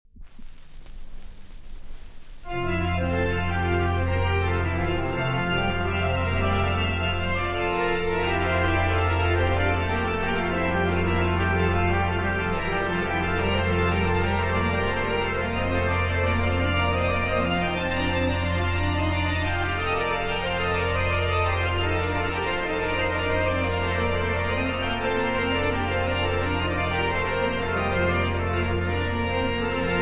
• organo
• toccate
• registrazione sonora di musica